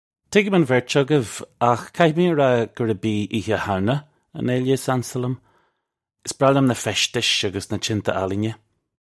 Pronunciation for how to say
Tiggim un verch uggiv akh kahee may uh rah gurrub ee Eeha Howna un ayluh iss ansa lyum! Iss braw lyum nuh feshtish uggus nuh chinteh ahleenya. (U)
This is an approximate phonetic pronunciation of the phrase.